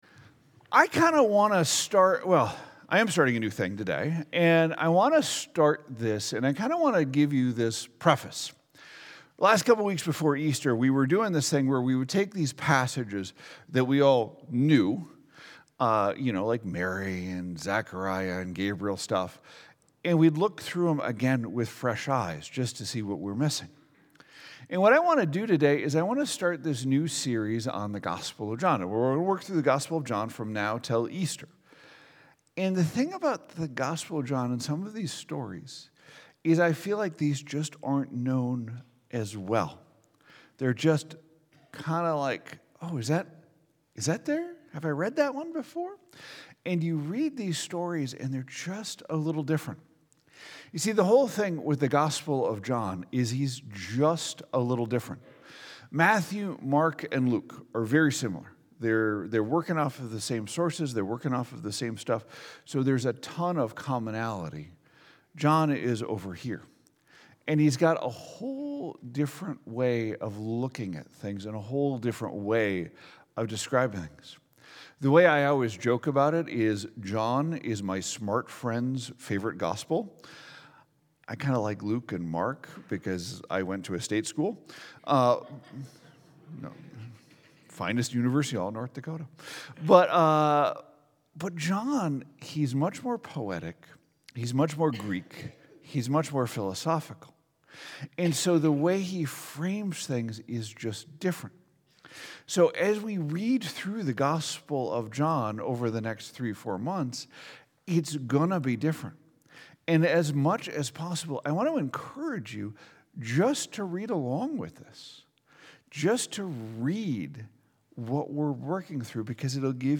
2025 Gospel of John Baptism Jesus John the Baptist Sunday Morning John the Baptist